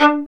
Index of /90_sSampleCDs/Roland - String Master Series/STR_Violin 1 vb/STR_Vln1 _ marc